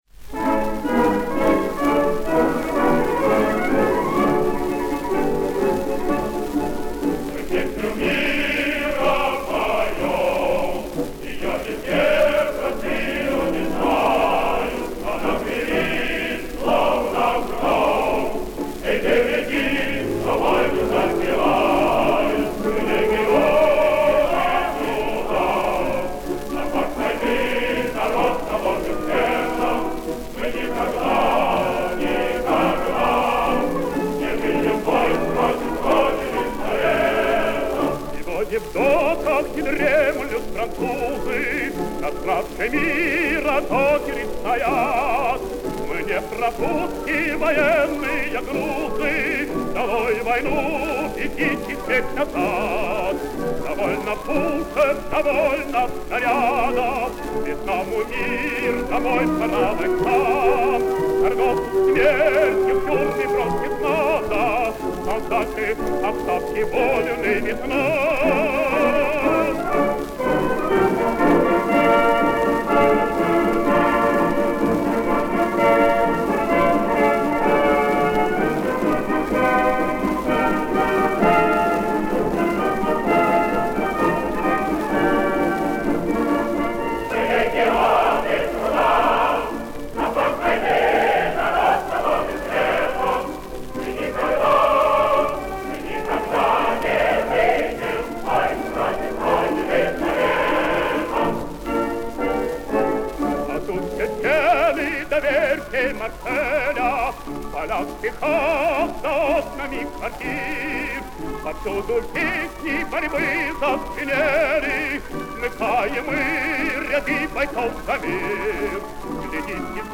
Запись, по-видимому, сделана с эфира
хор и оркестр Исполнение 1951г.